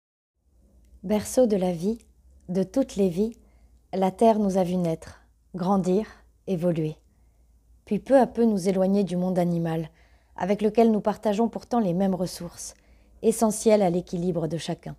Voix off
5 - 30 ans - Mezzo-soprano